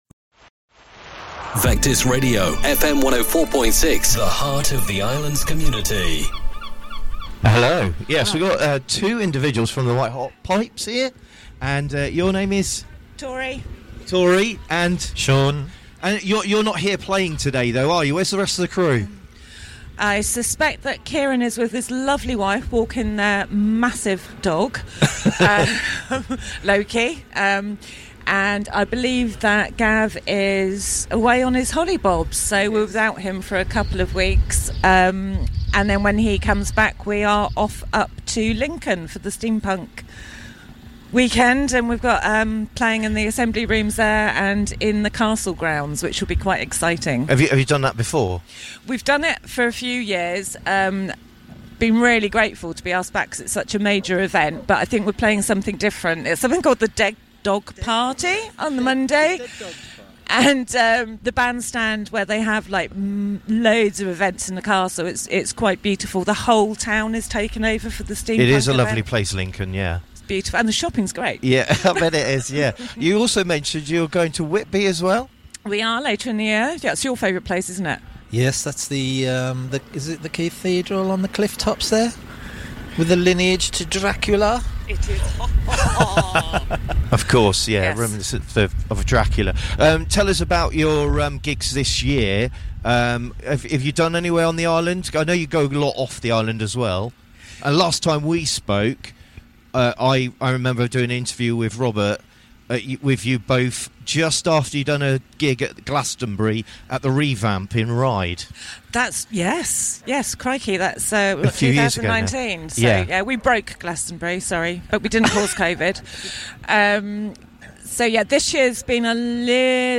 at The Chale Show 2025.